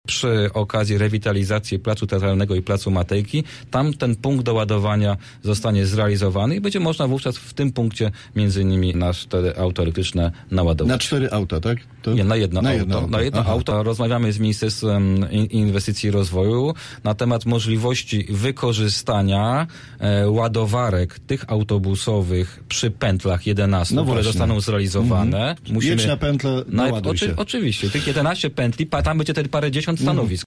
Do sprawy odniósł się wiceprezydent miasta. Krzysztof Kaliszuk twierdzi, że stacja ładowania dla aut osobowych powstanie na Placu Matejki, gdzie obecnie prowadzona jest rewitalizacja.